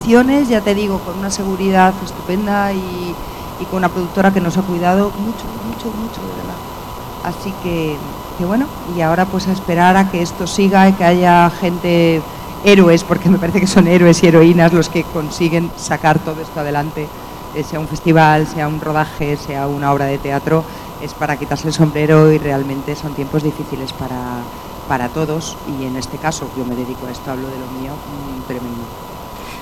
Las cuatro estrellas del cine español, con reconocimiento de crítica y público, han contado sus experiencias en una mesa redonda Movistar+ que se ha desarrollado en el Teatro Cervantes